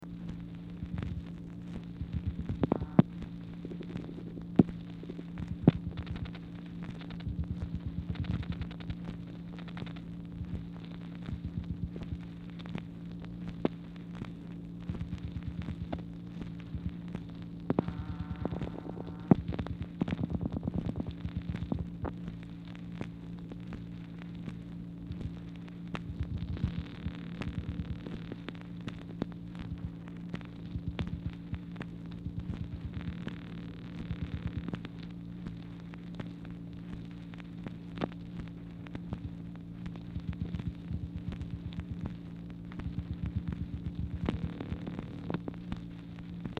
Telephone conversation # 3398, sound recording, MACHINE NOISE, 5/11/1964, time unknown | Discover LBJ
Format Dictation belt
White House Telephone Recordings and Transcripts Speaker 2 MACHINE NOISE